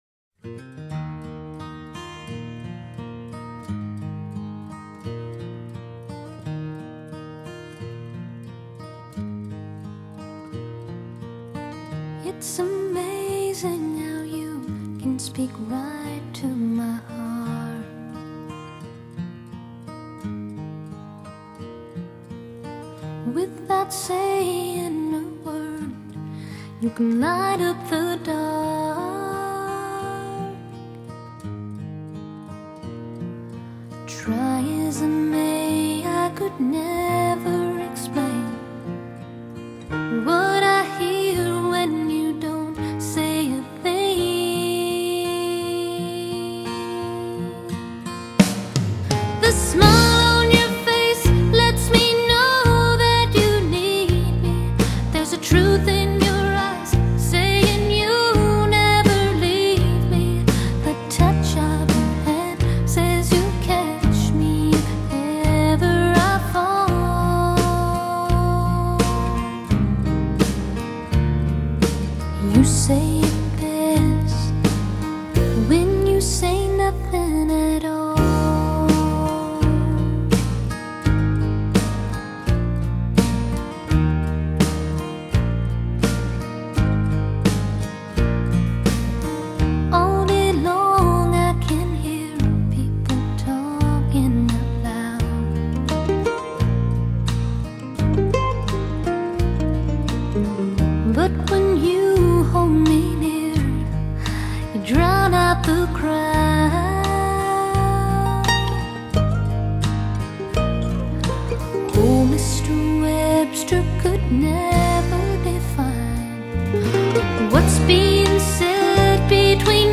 这种音乐有两三部和声，激烈的节奏，不受约束的情感。
这样的声音里，甜美的睡一个午觉，Banjo轻妙， Fiddle光滑，不必担心它们会吵着你。